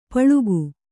♪ paḷugu